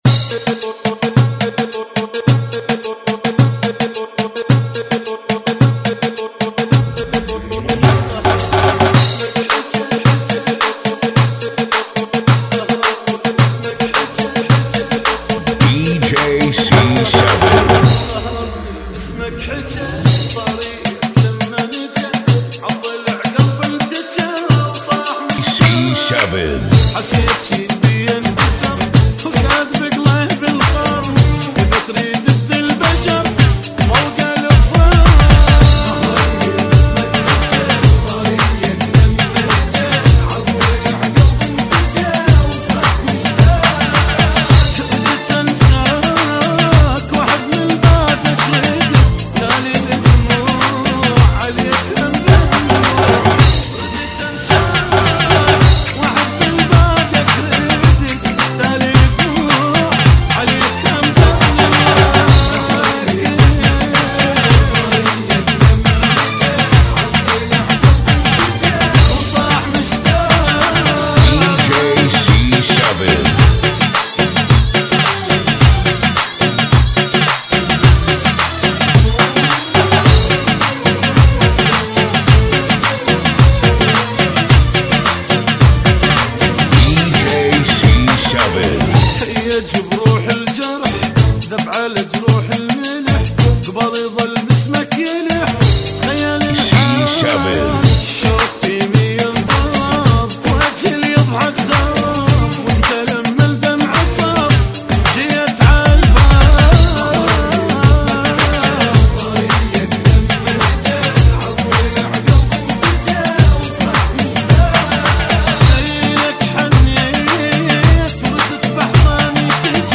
BPM 108